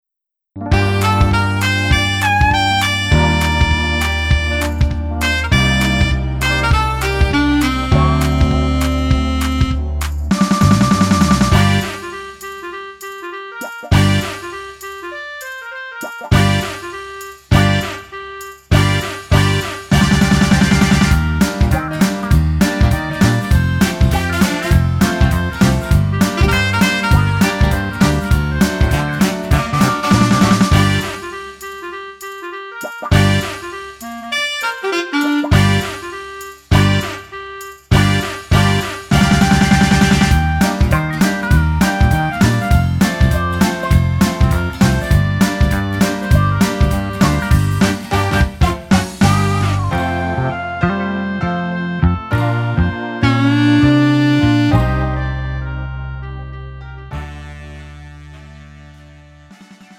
음정 원키 3:08
장르 가요 구분 Pro MR